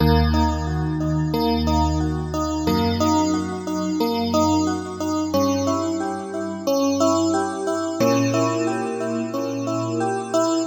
描述：键入：Emin 速度：90bpm 一些俱乐部类型的Trap/hiphop循环。FX反向延迟
标签： 90 bpm Rap Loops Synth Loops 3.59 MB wav Key : E
声道立体声